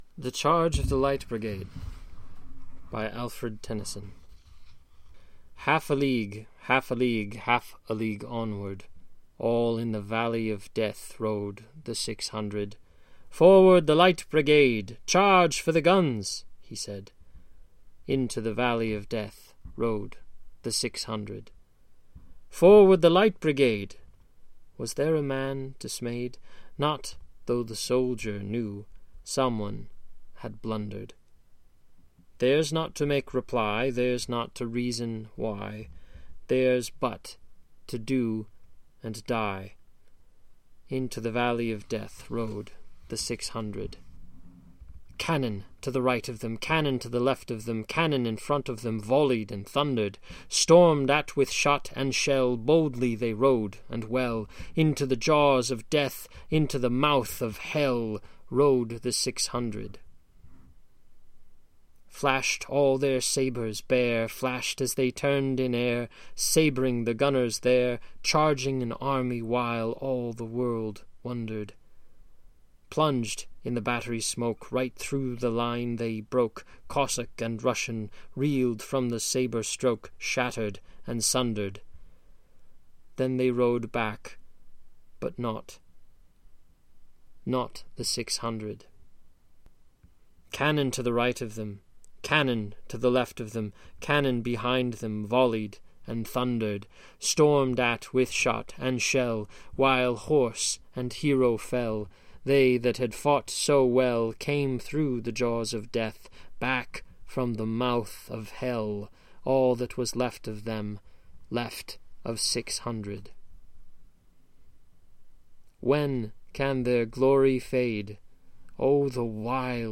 The audio and print productions featured here are amateur creations of our staff and supporters.  We hope the story-telling and creativity of these projects make up for any lack in technical expertise.